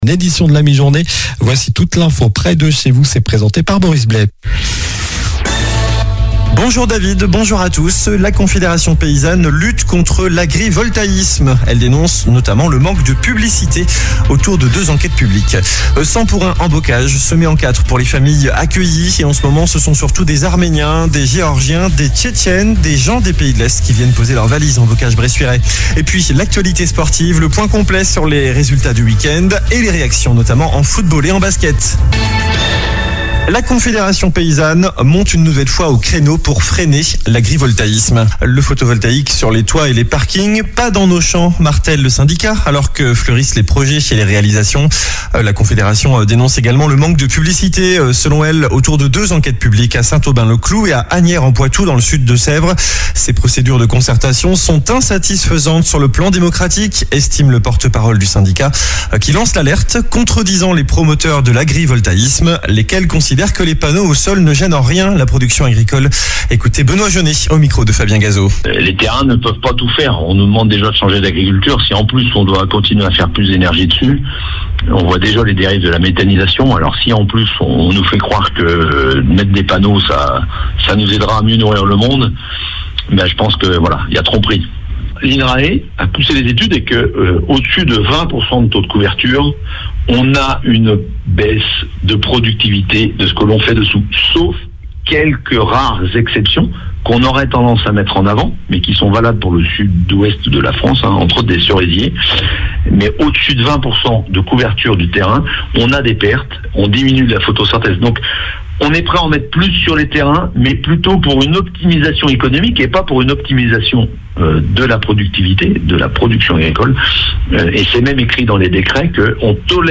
Journal du lundi 17 mars (midi)